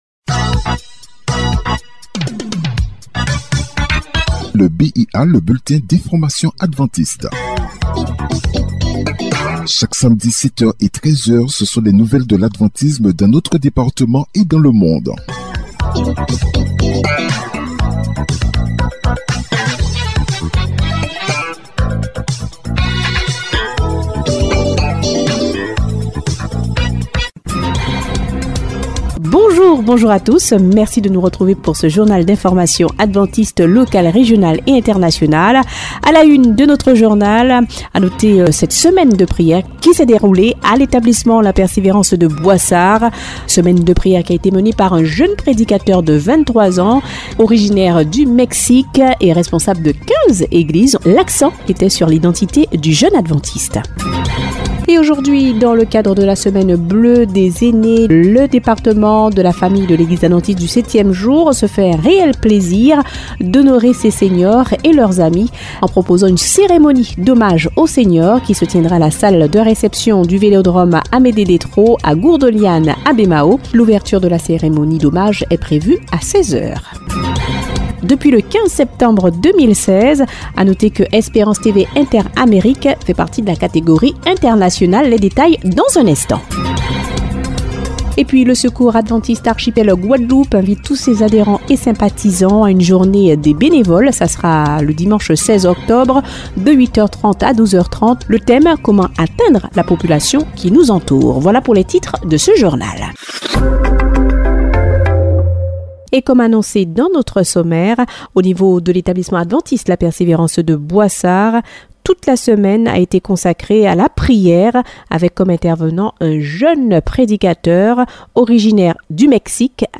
Le journal peut être écouté directement ou il peut être téléchargé.